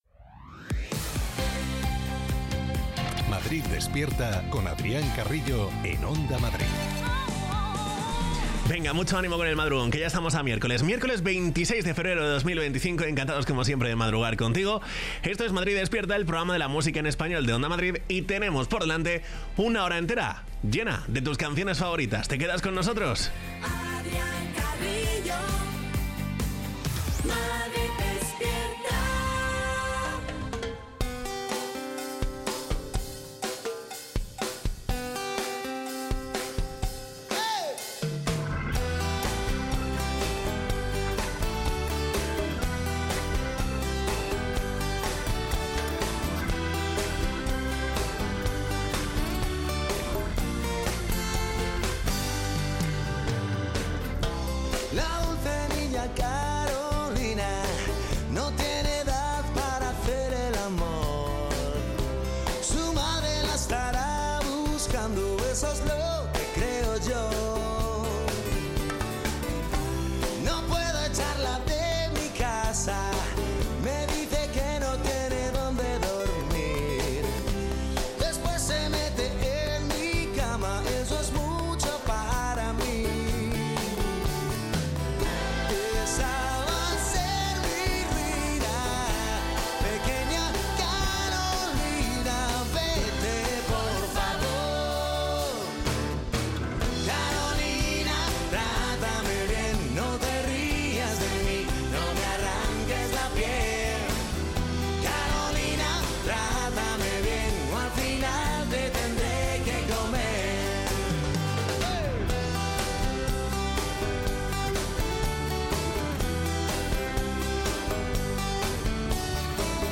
Morning show